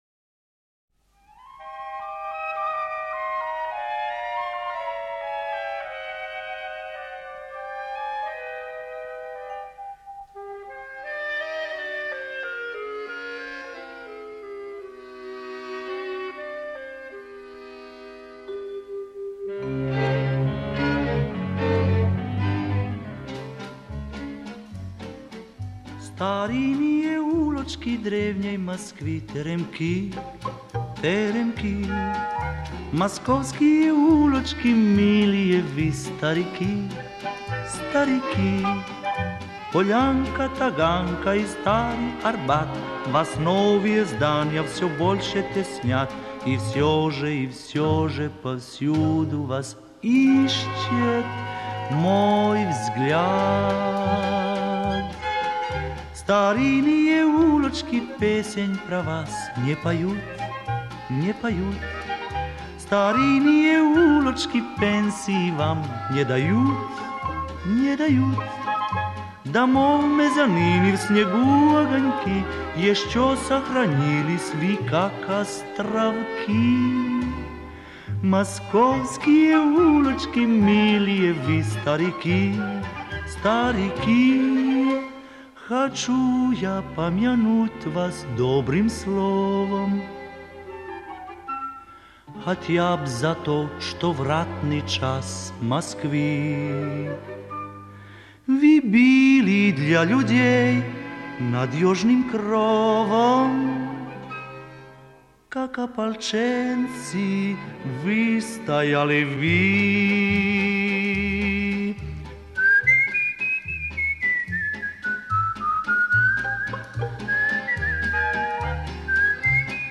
(пародия)